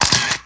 camera02.mp3